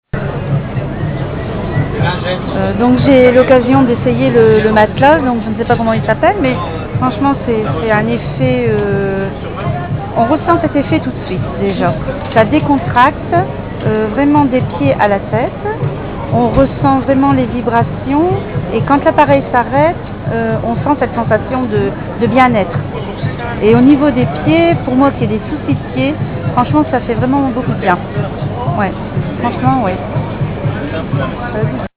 Massage sur Braderie de Lille 2010
Ci-joint plusieurs témoignages d'utilisateurs comblés.